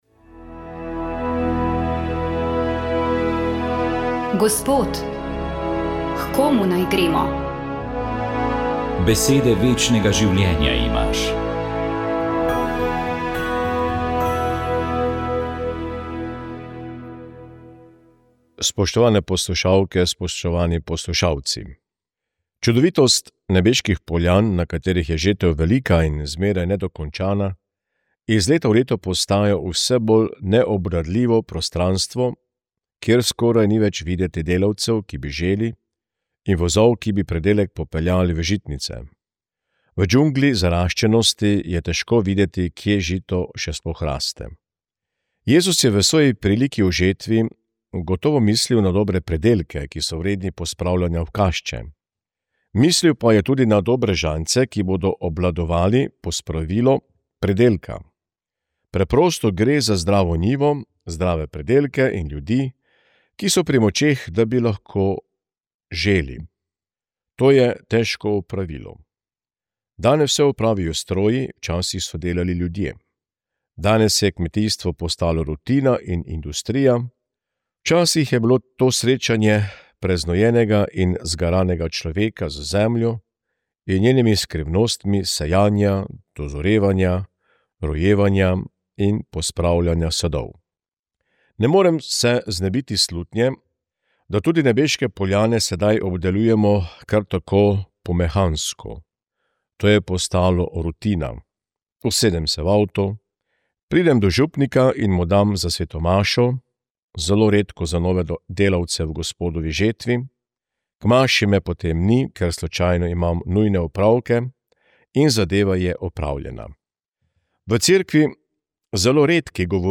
Duhovni nagovor je pripravil Koprski škof msgr. dr. Jurij Bizjak.